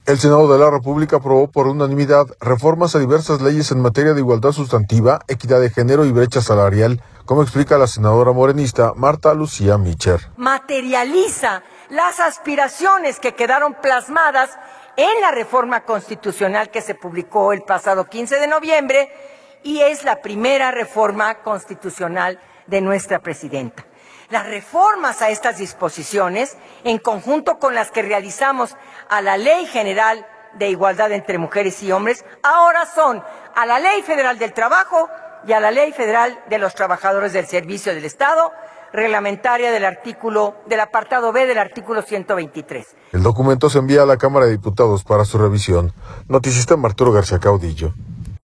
El Senado de la República aprobó por unanimidad reformas a diversas leyes en materia de igualdad sustantiva, equidad de género y brecha salarial, como explica la senadora morenista Martha Lucía Micher.